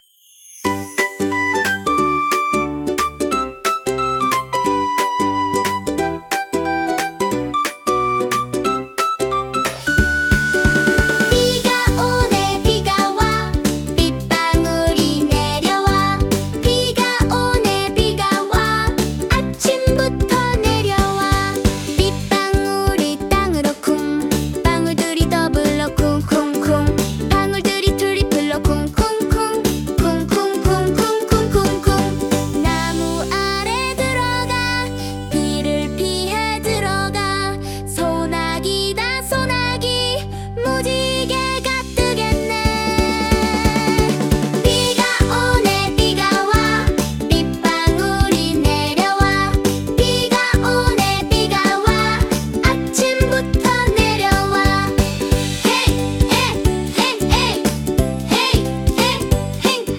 동요가 좀 귀엽네요